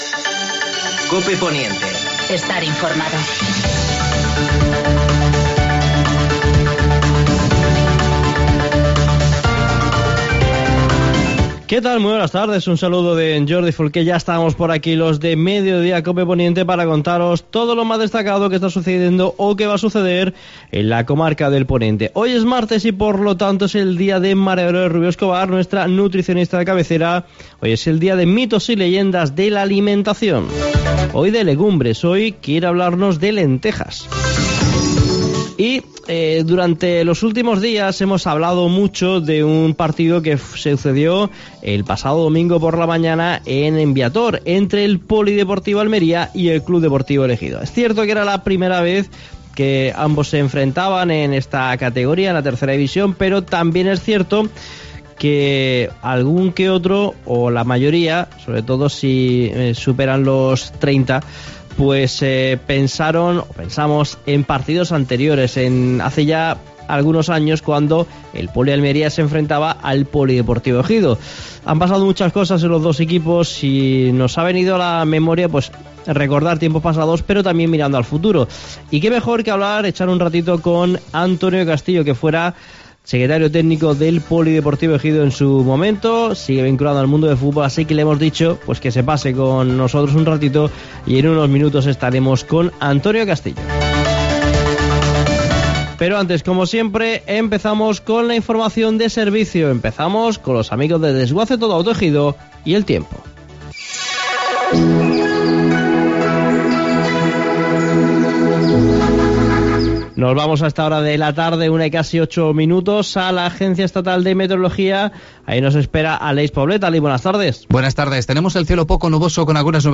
AUDIO: Actualidad en el Poniente. Entrevista